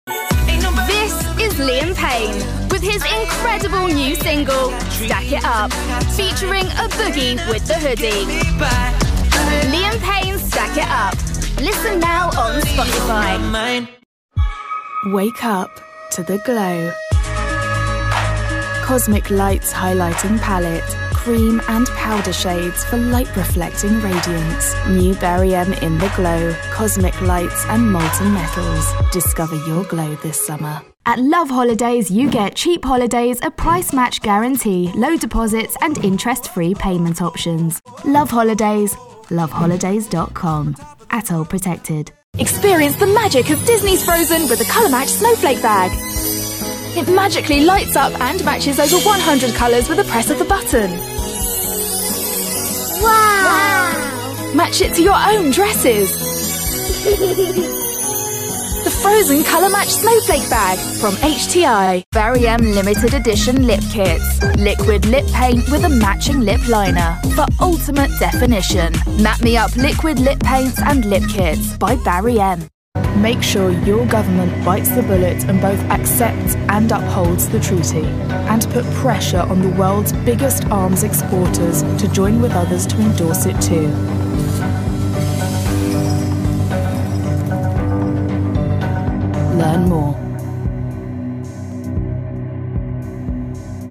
Find and hire professional voice over artists in the Saint Pée Sur Nivelle.
Female 20s , 30s , 40s , 50s British English (Native) Bright , Confident , Corporate , Engaging , Friendly , Natural , Reassuring , Warm , Witty , Versatile